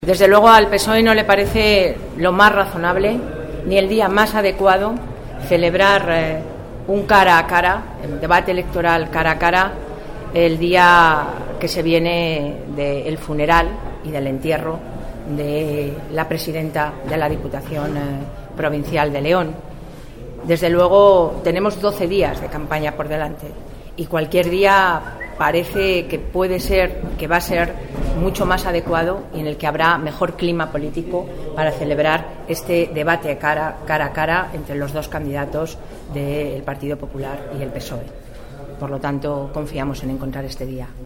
Declaraciones de Soraya Rodríguez sobre la conveniencia de retrasar el debate Valenciano-Cañete 13-05-14